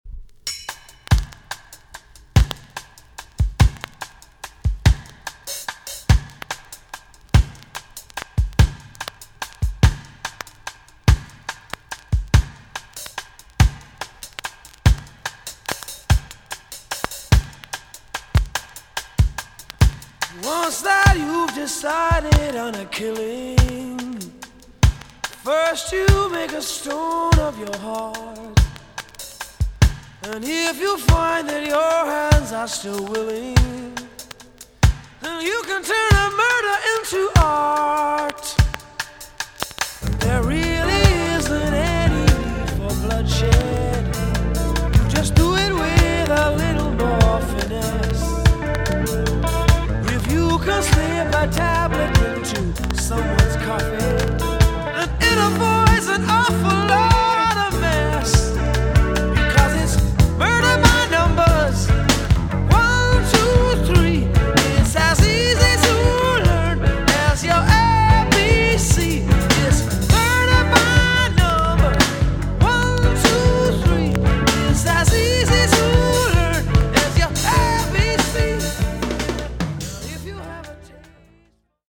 EX-~VG+ 少し軽いチリノイズが入る箇所があります。
1983 , イギリスのロックバンド。